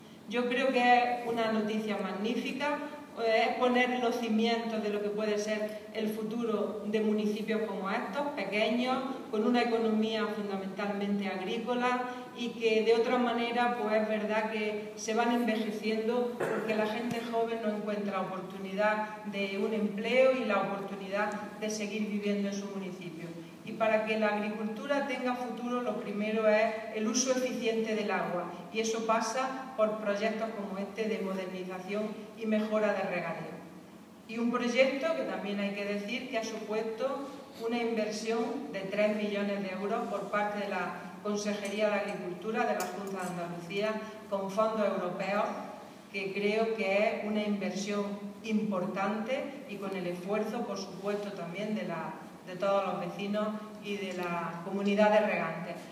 Declaraciones de Carmen Ortiz sobre el proyecto de modernización y mejora de regadíos de la Comunidad de Regantes de Dólar